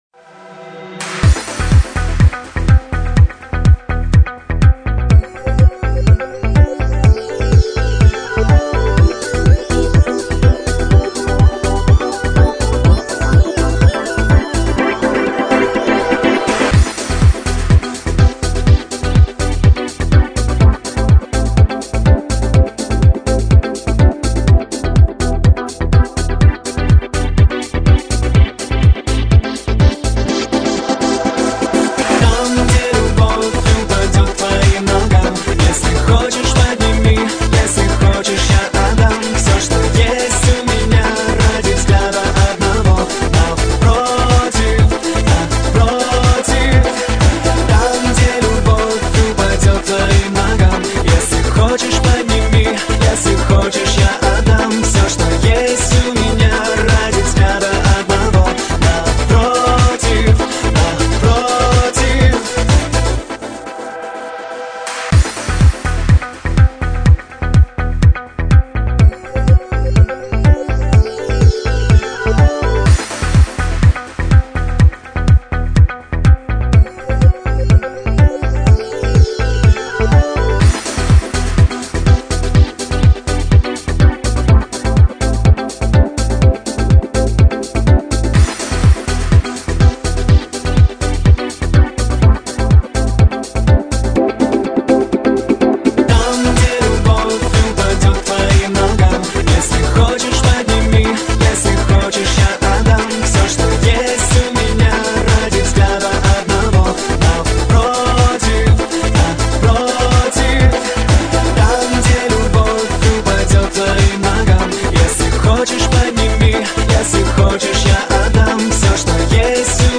Отличный ARTминус